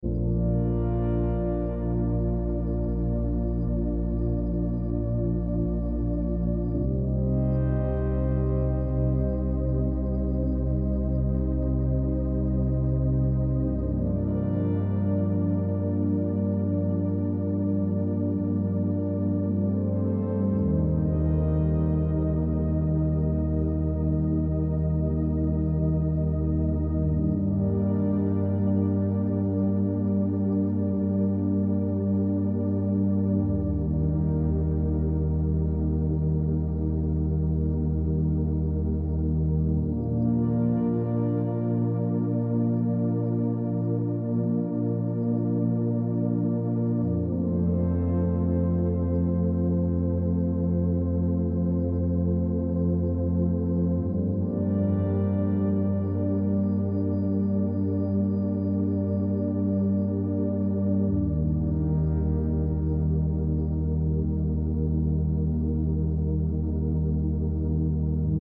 936 hz